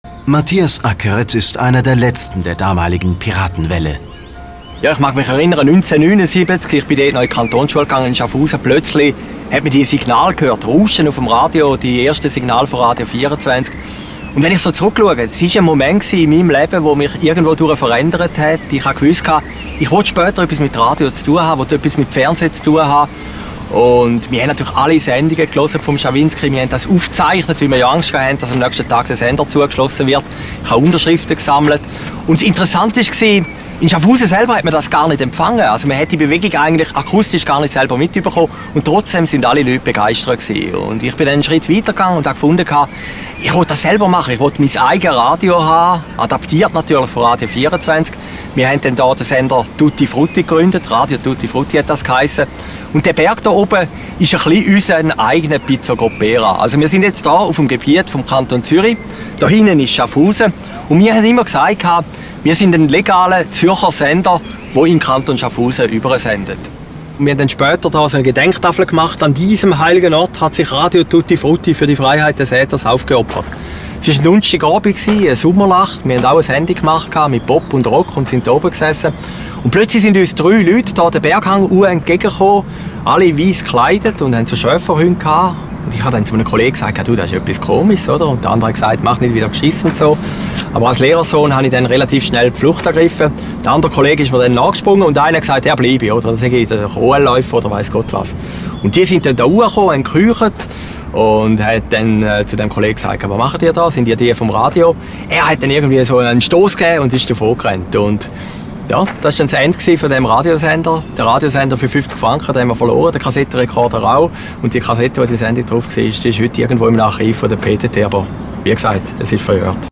documentary movie: Jolly Roger, "A chapter of media history"